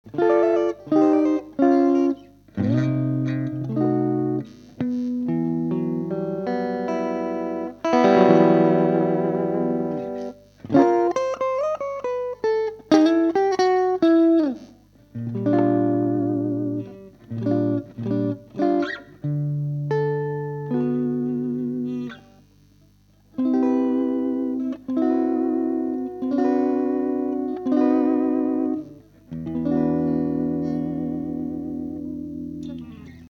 ANTONIO SPECIAL Demo(520kbMP3)